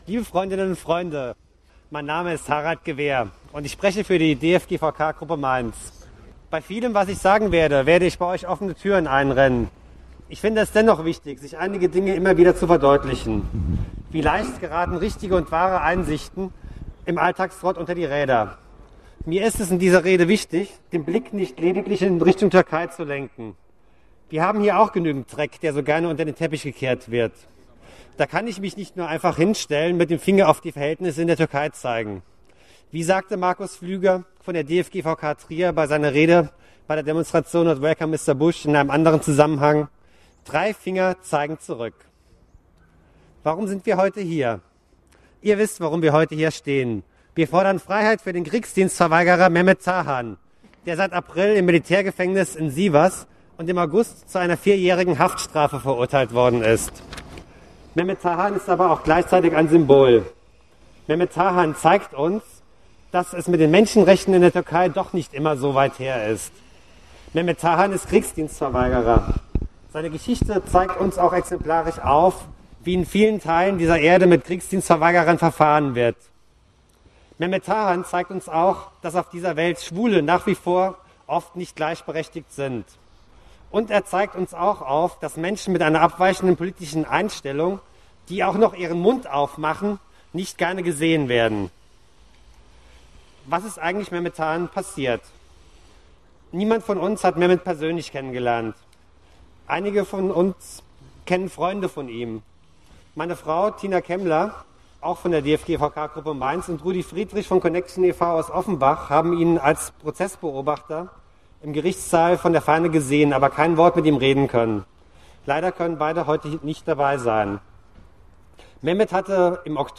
Rede als MP3